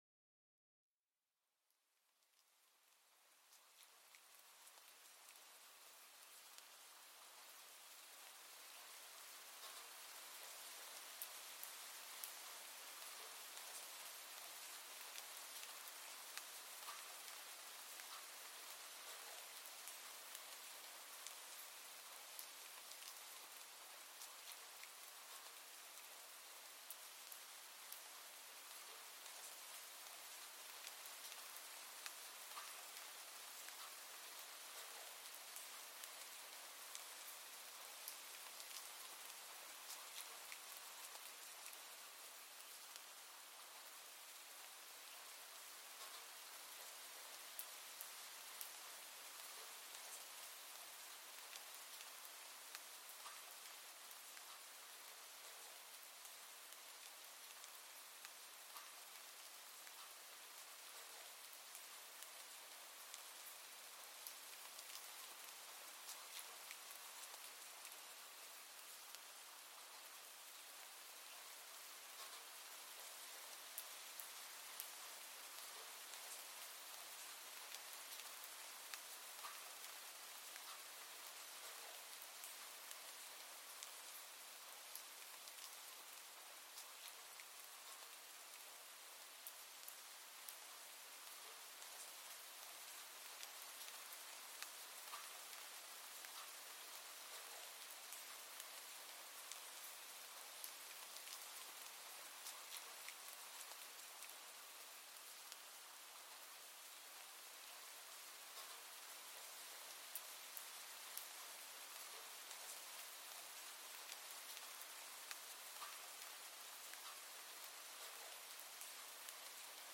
Entspannender Regen: Hören und den Geist beruhigen
Entdecken Sie das beruhigende Geräusch von sanft auf Blätter fallendem Regen, das eine entspannende und ruhige Atmosphäre schafft. Perfekt zum Entspannen nach einem stressigen Tag oder um den Schlaf zu fördern. Lassen Sie sich von der natürlichen Melodie des Regens davontragen.Dieser Podcast entführt Sie in die Natur mit authentischen Aufnahmen von Umgebungsgeräuschen.